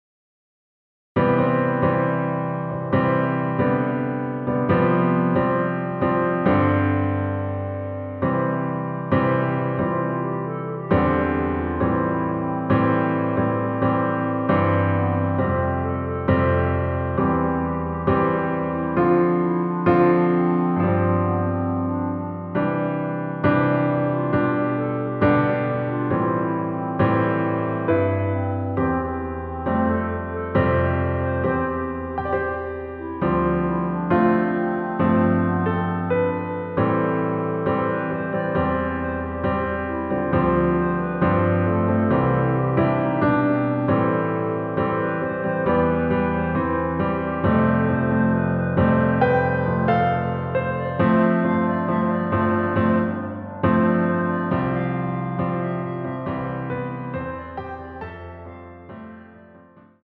반주를 피아노 하나로 편곡하여 제작하였습니다.
원키에서(-1)내린 (Piano Ver.)멜로디 MR입니다.